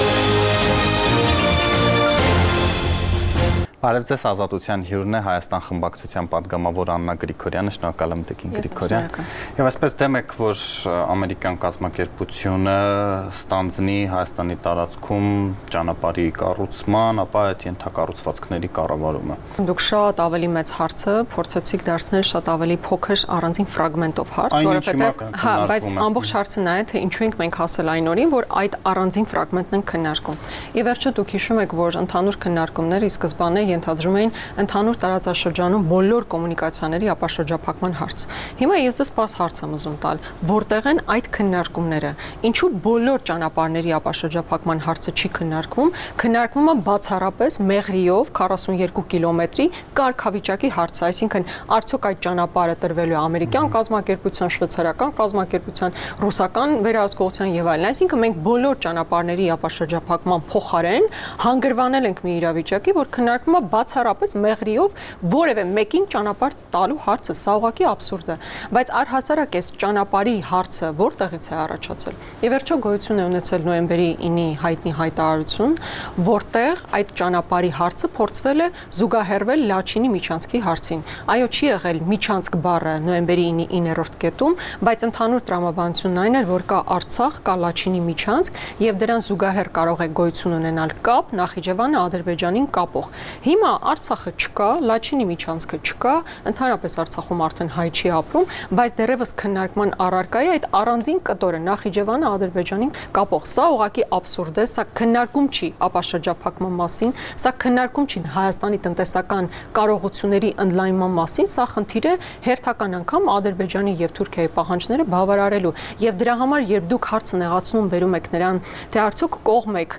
Տեղական եւ միջազգային լուրեր, ռեպորտաժներ, հարցազրույցներ: